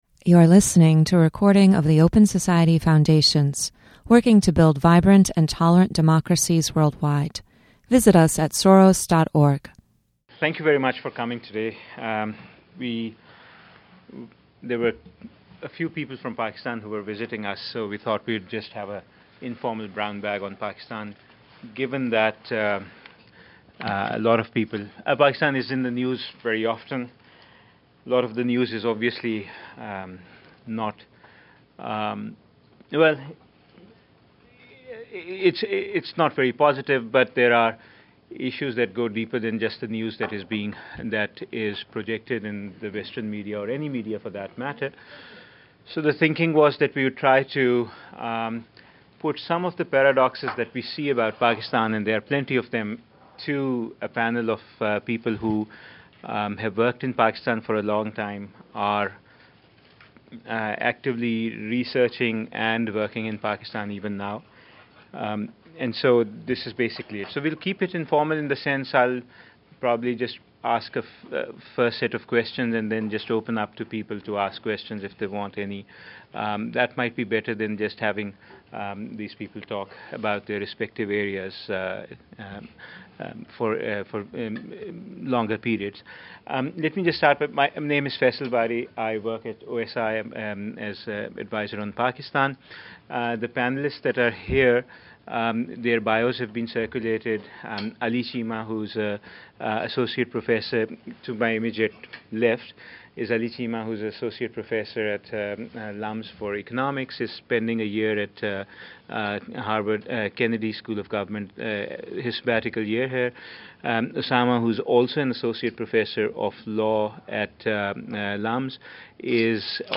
This Open Society Foundations event features a conversation with three Pakistani experts from academia, the legal profession, and journalism.